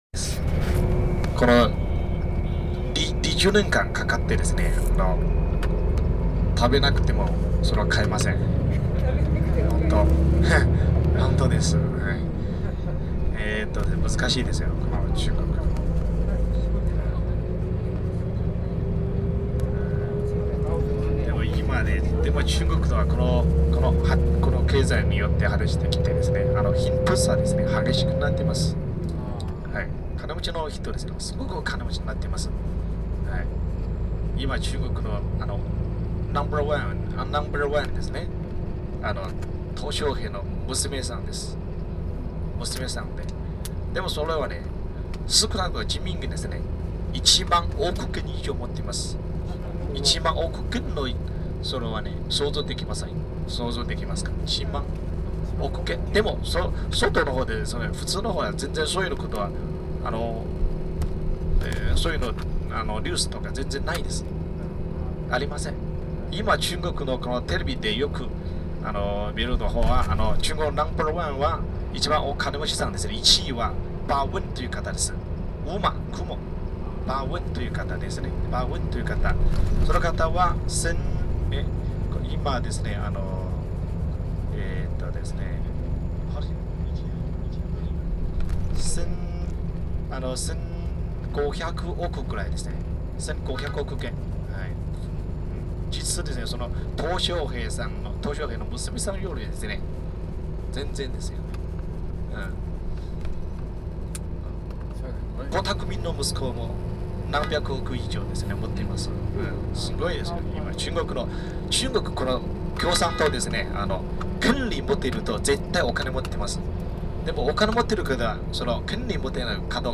日本語もなかなか上手い　とても愛想がいい